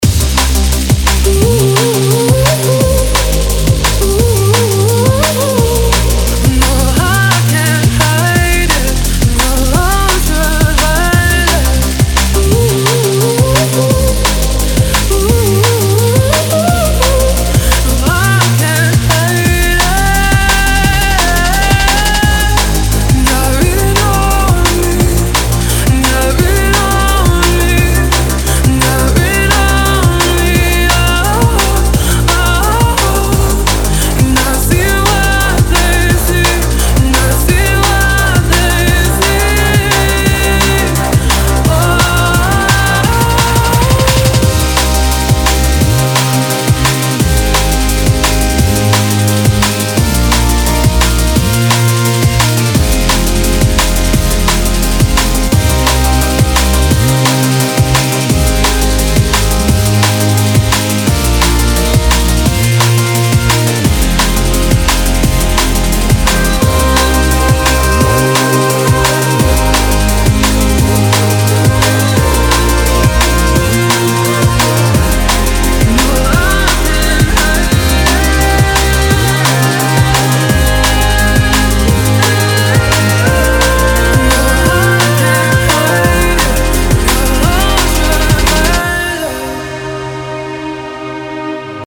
• Качество: 320, Stereo
remix
Electronic
красивый женский голос
drum n bass
Стиль: drum&bass